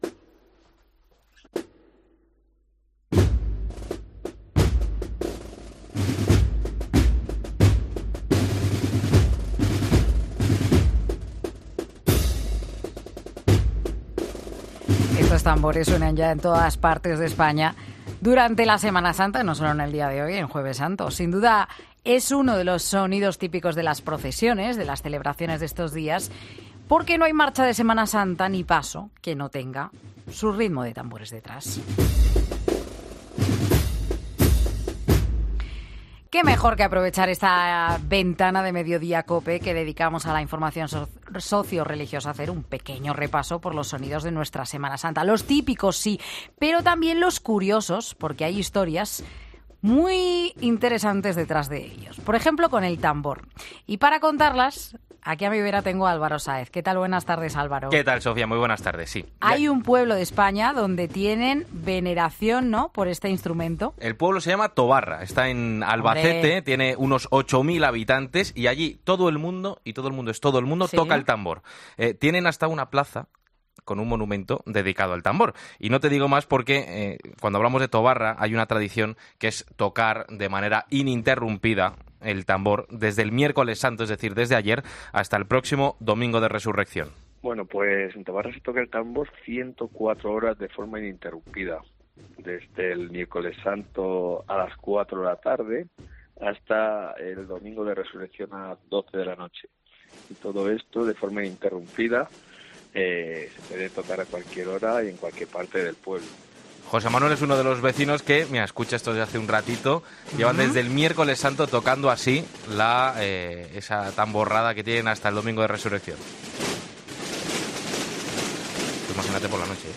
Hacemos un repaso por los sonidos de la pasión en España descubriendo tradiciones que permanecen intactas desde hace siglos
Sin duda uno de los sonidos más significativos es el de los tambores.
Es una una especie de trompeta alargada, de casi un metro de largo y la manera de tocarlo no es difícil: son solo tres toques, dos cortos más graves y uno muy largo.